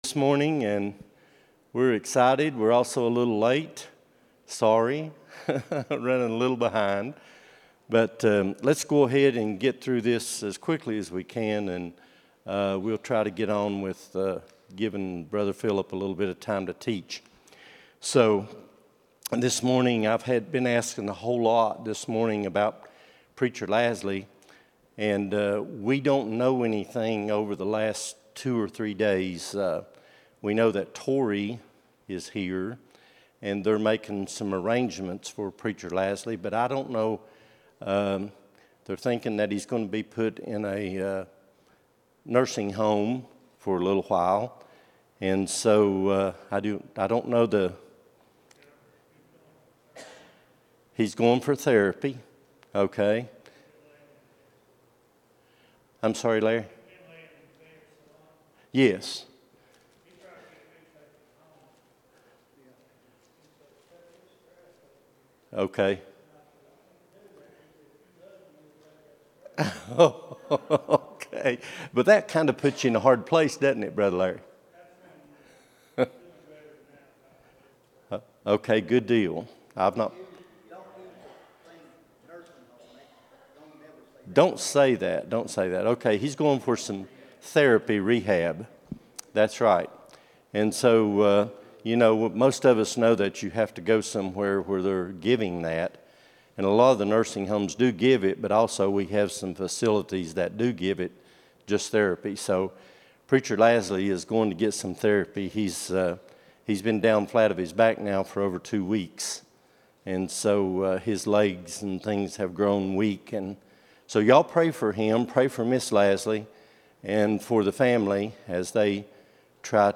12-08-24 Sunday School | Buffalo Ridge Baptist Church